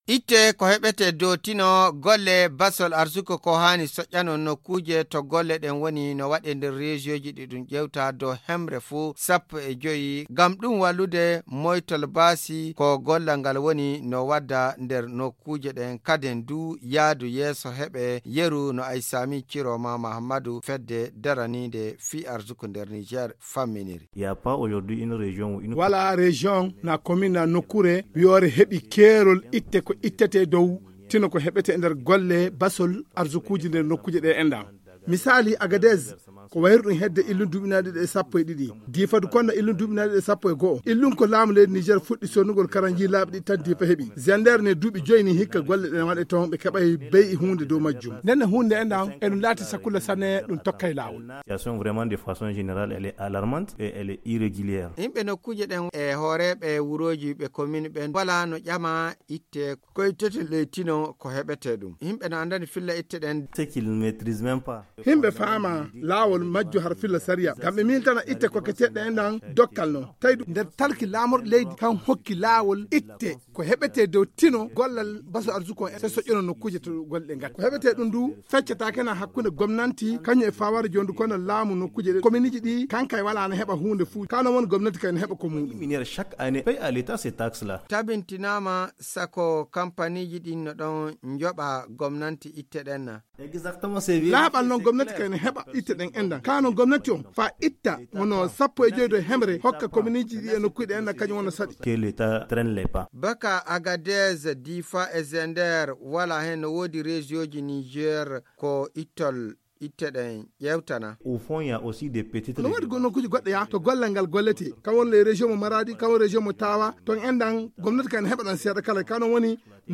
dans un entretien